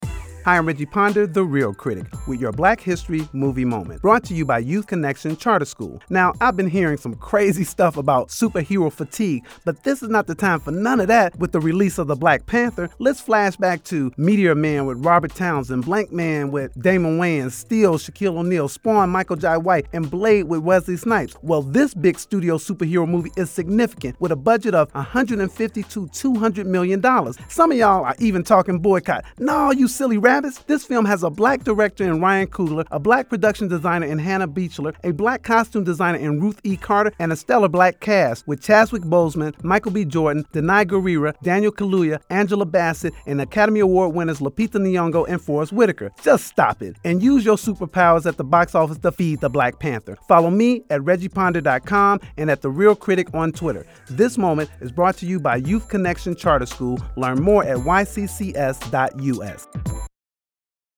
Movie Moment – My Review